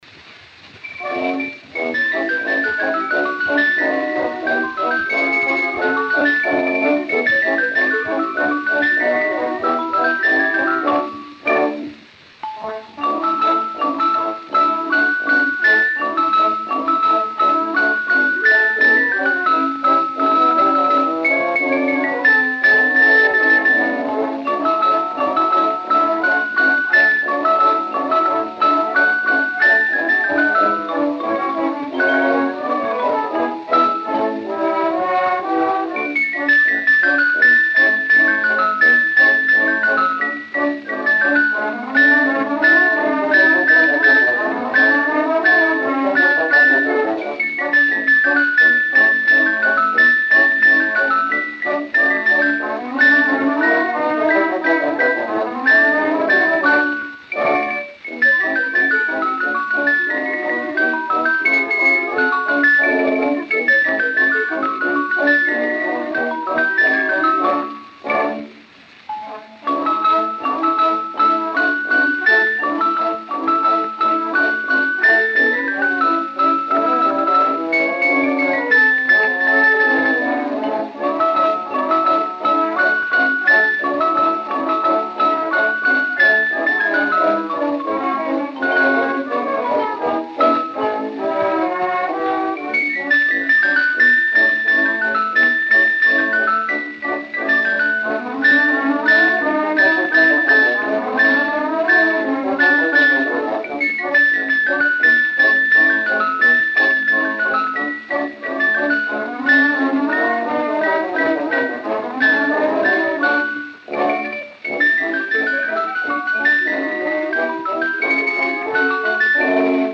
Disco de 78 rotações, também chamado "78 rpm", gravado em apenas um dos lados e com rótulo "rosa".
A performance da música foi executada pelo "Solista da Casa".